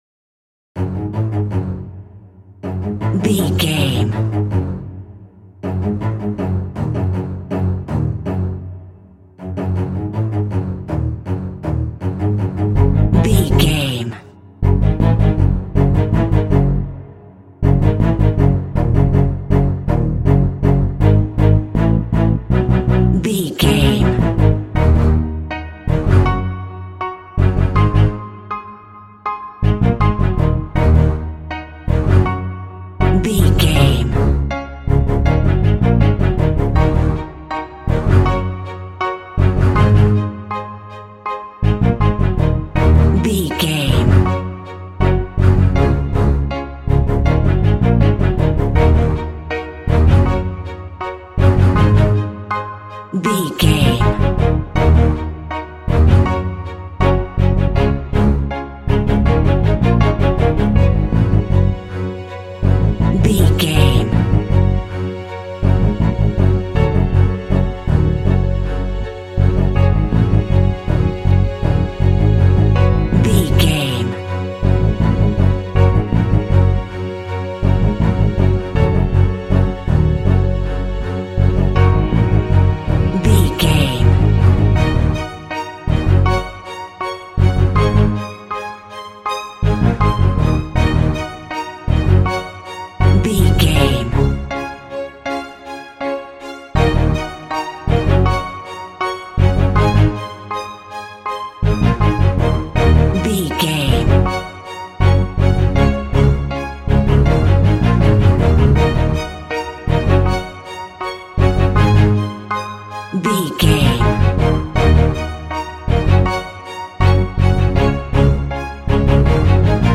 Fast paced
Aeolian/Minor
F#
ominous
tension
strings
piano